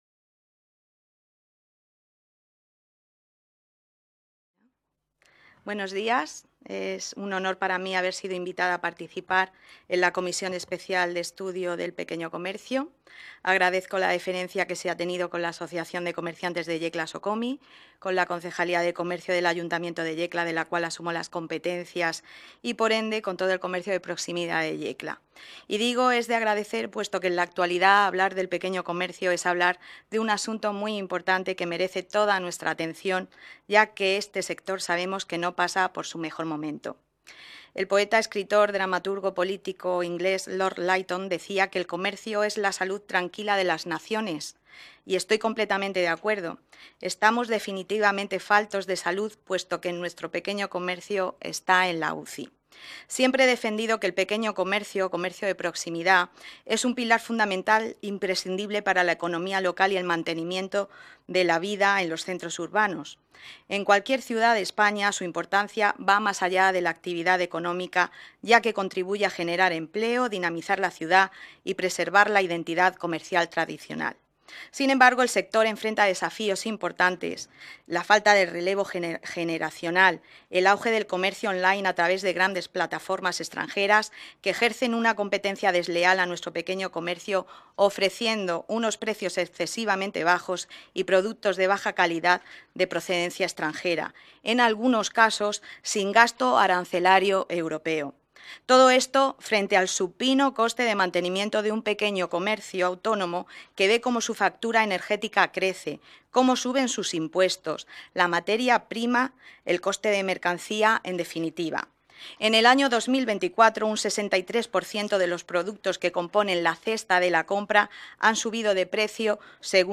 Ruedas de prensa tras la Comisión Especial de Estudio sobre el Pequeño Comercio en la Región de Murcia
• Magdalena Martínez Rovira, concejala de Industria, Comercio, Hostelería, Mercados y Consumo del Ayuntamiento de Yecla